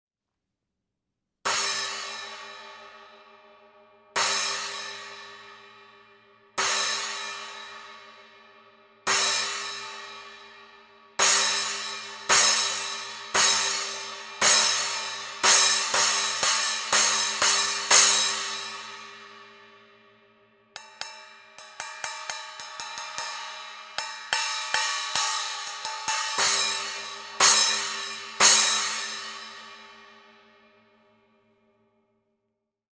10″ Armor Holey Splash Cymbals (Approx 290 grams):
10__Armor_Holey-Splash.mp3